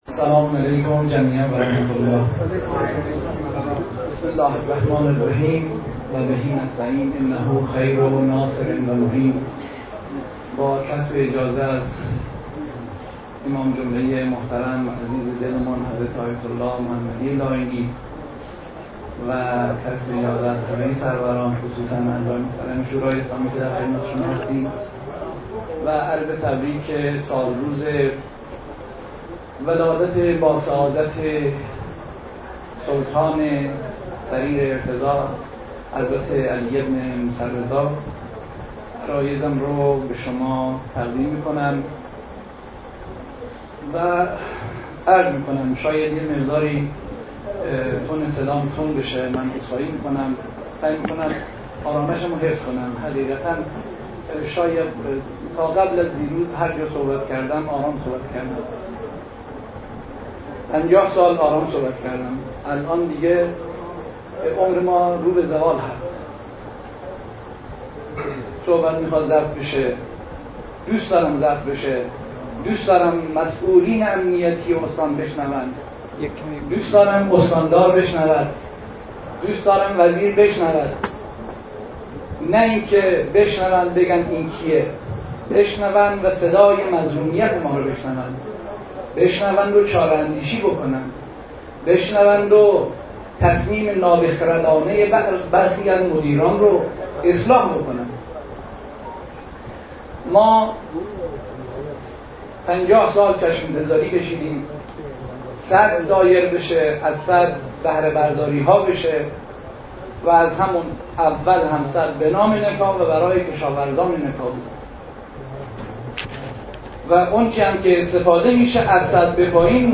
فایل صوتی سخنان رئیس شورای اسلامی نکا .